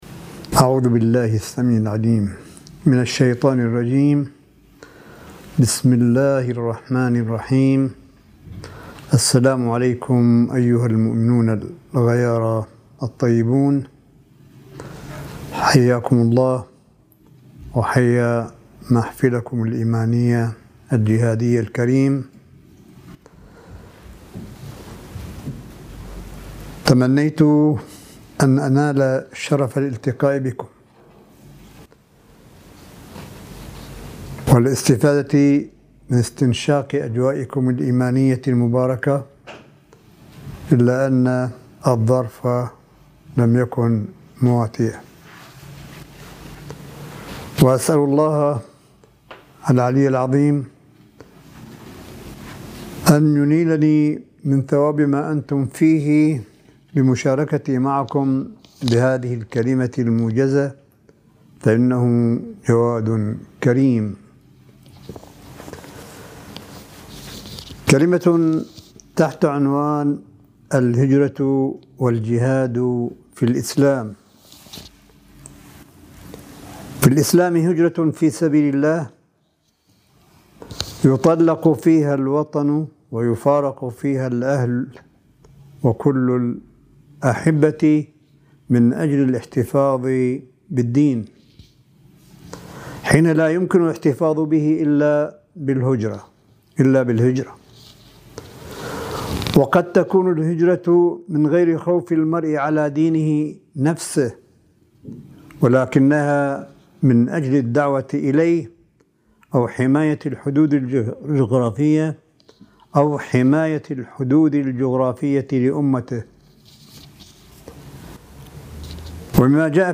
صوت : كلمة آية الله قاسم في مؤتمر “المجاهدون في الغربة” في مدينة دامغان 28 اكتوبر 2019م